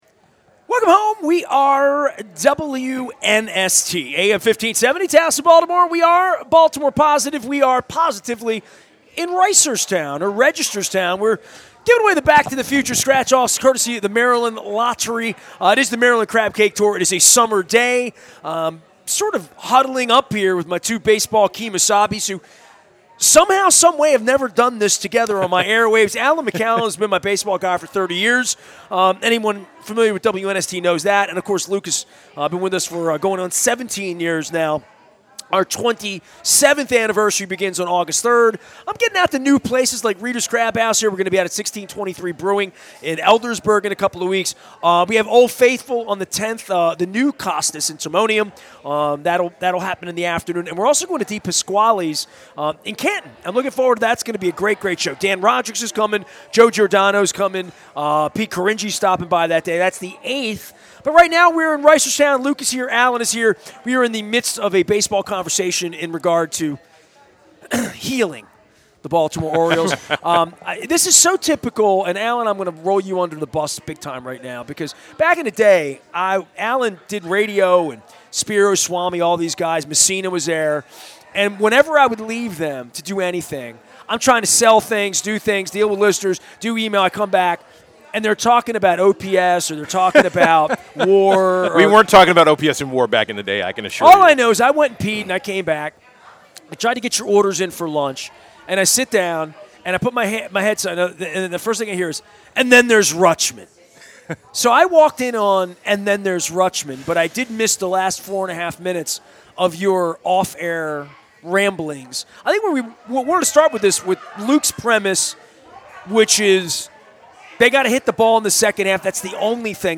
sports huddle